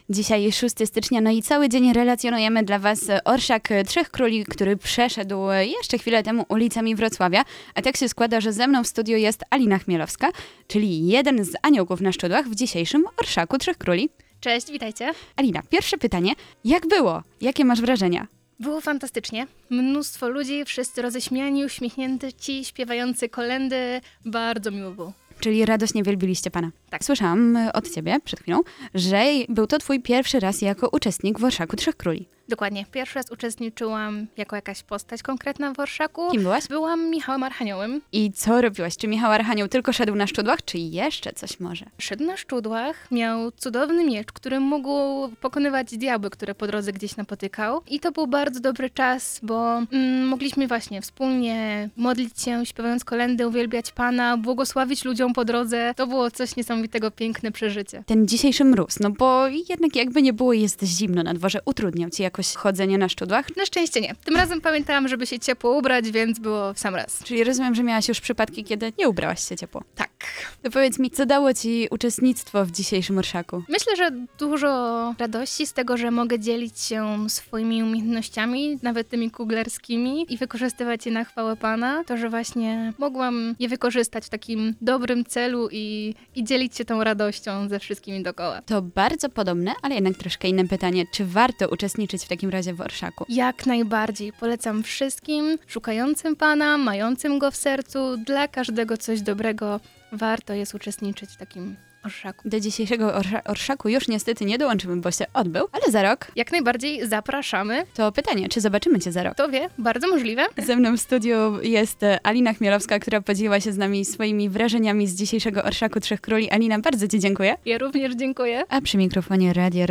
W krótkiej rozmowie opowiedziała nam o swoich wrażeniach.